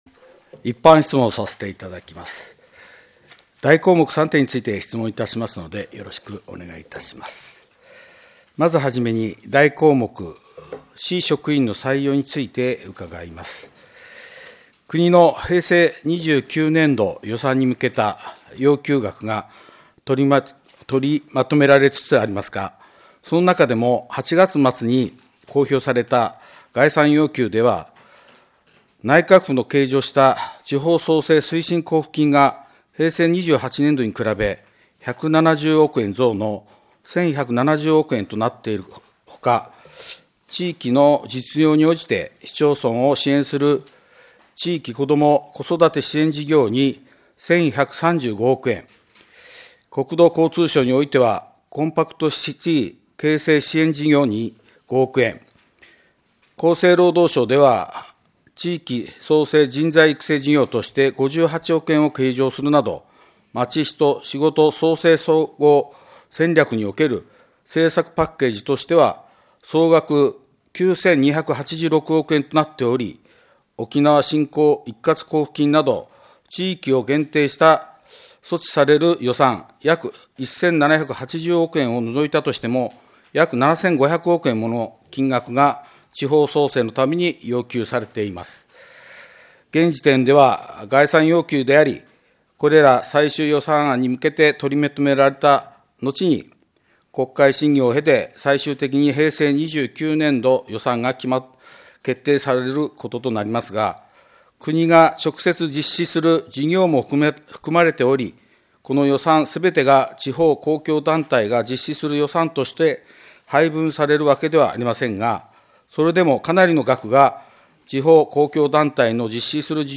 平成28年第4回定例会 一般質問録音音声 - 留萌市ホームページ
議会録音音声